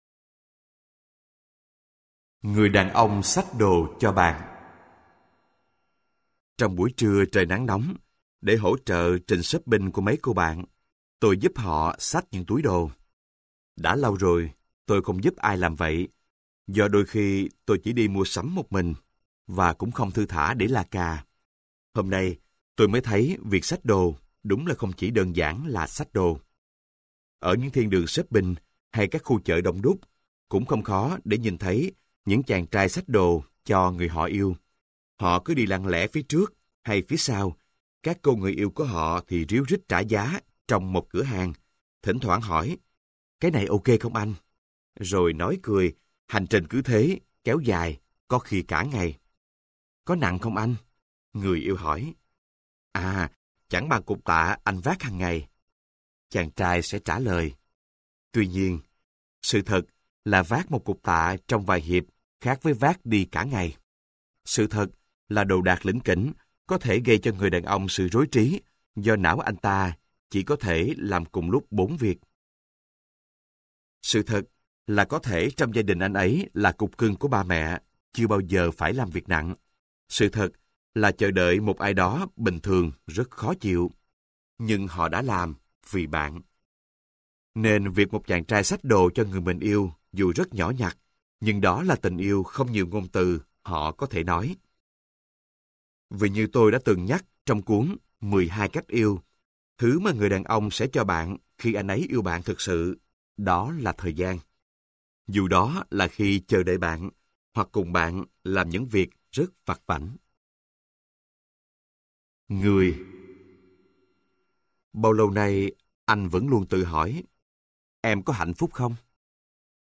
Sách nói Người Lớn Không Khóc - Hamlet Trương - Sách Nói Online Hay